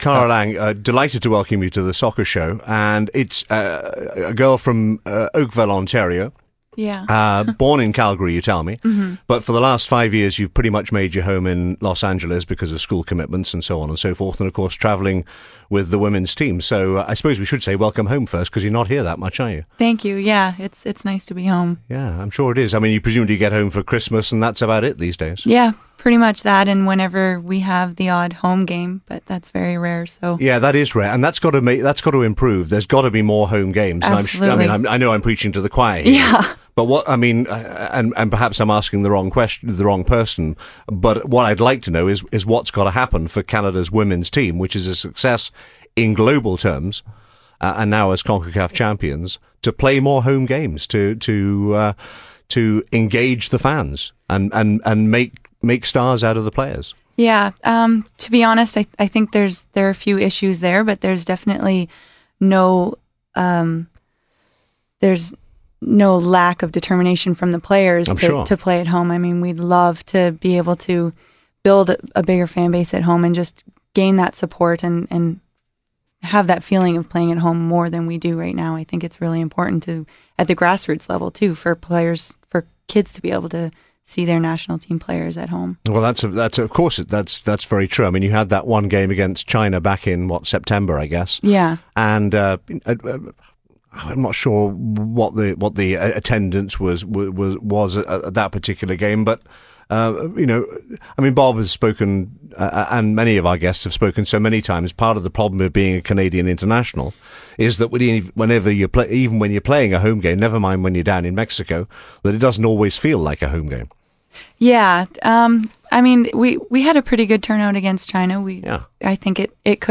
Kara Lang Audio Interview - January 9th, 2010 Kara Lang, Canadian International, had to retire earlier in the week due to persistant knee problems due to injuries throughout her playing career for both the club and country.